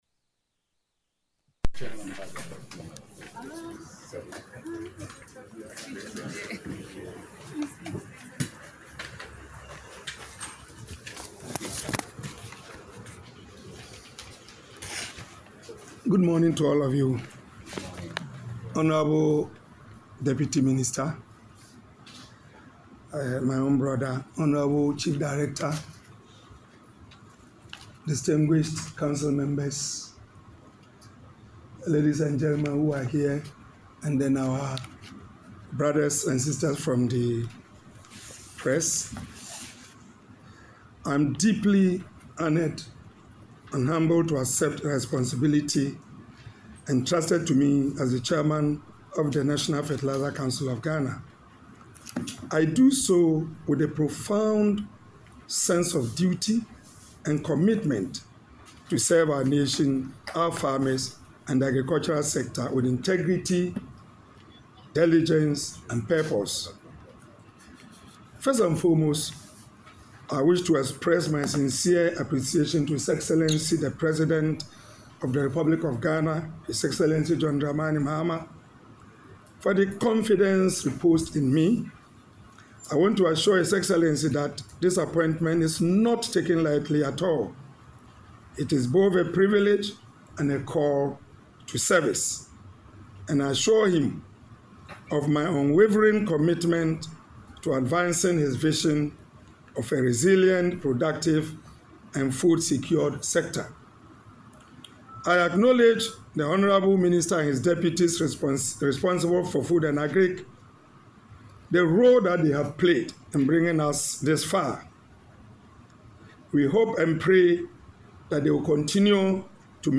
During the swearing-in ceremony, which took place on Tuesday, 30 December 2025, at the ministry’s conference room, Hon. Amadu Sorogo expressed gratitude to President H.E. John Dramani Mahama and the two ministers of MoFA for the confidence reposed in him and the board.